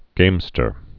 (gāmstər)